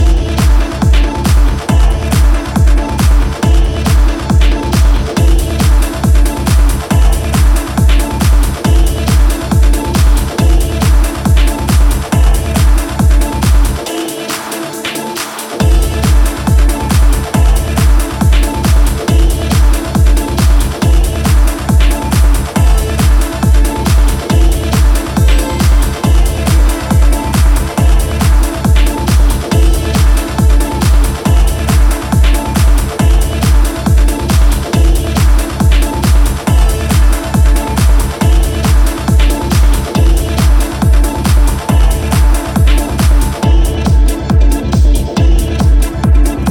Two raw, distinctive, ultra-dry dancefloor slammers